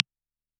Impulse Response 1 DOWNLOAD